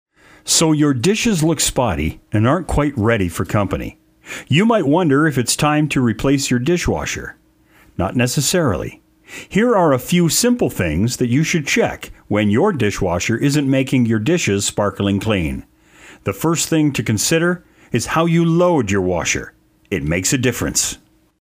Friendly and informative
English (North American)
Friendly and informative.mp3